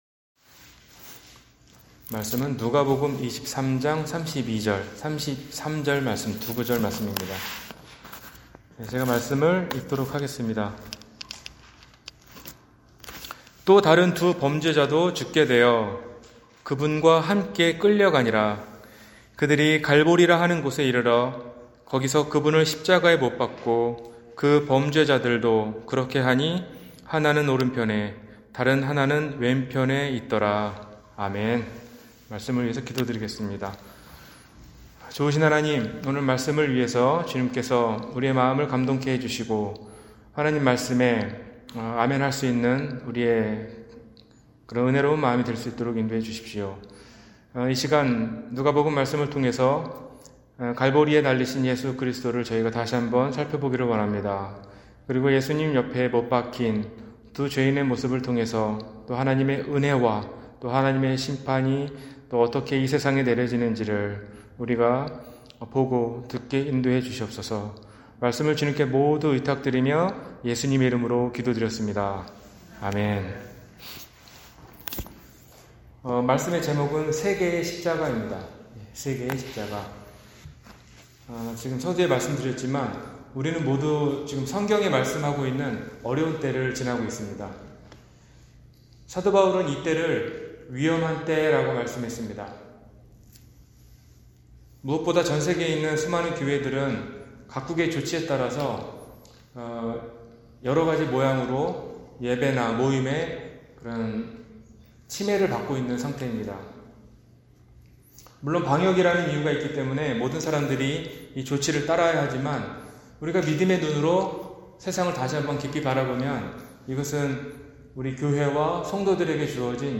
세개의 십자가- 주일설교